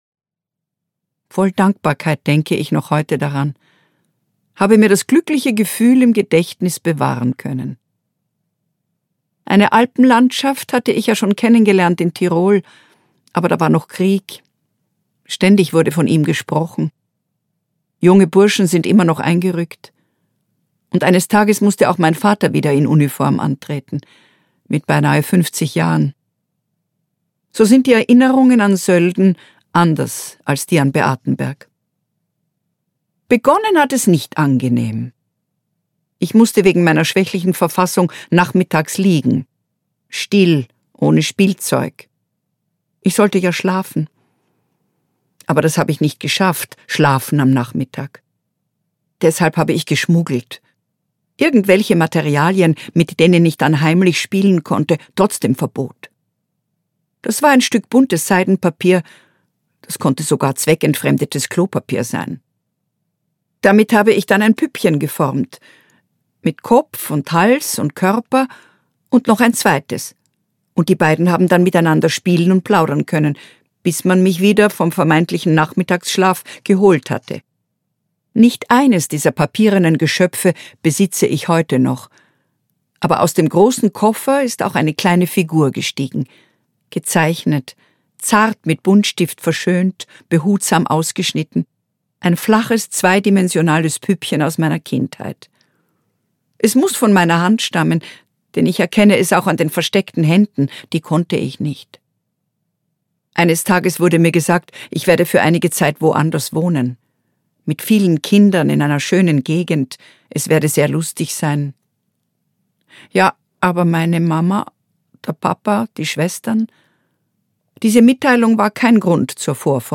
Ich bin der Weiße Clown - Christiane Hörbiger - Hörbuch